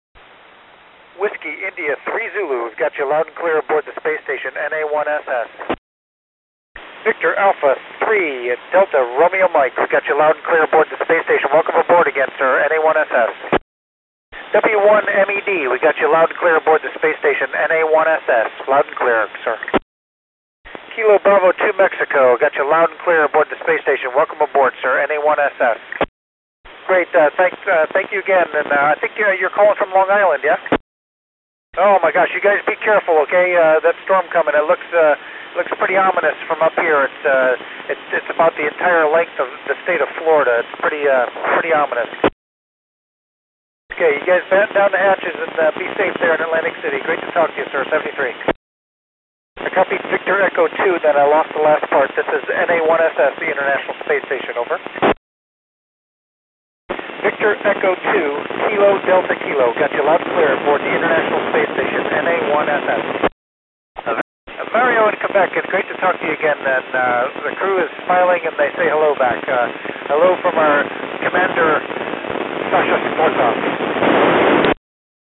NA1SS warns station of Hurricane Earl